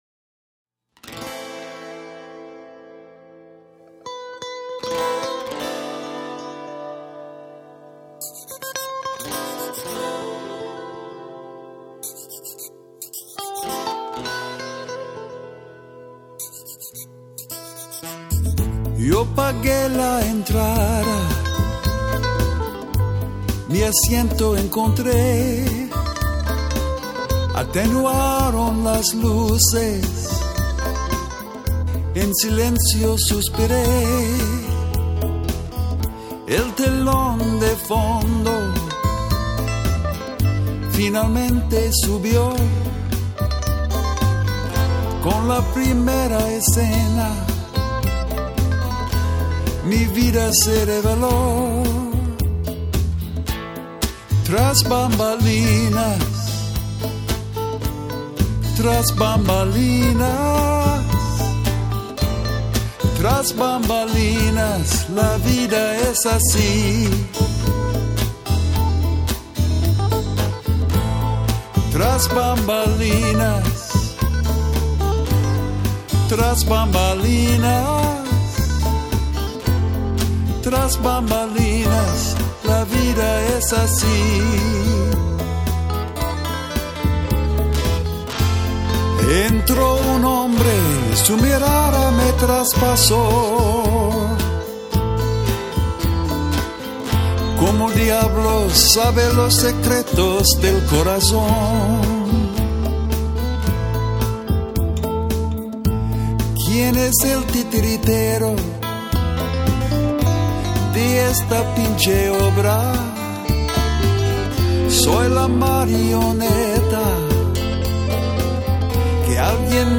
Guitar lead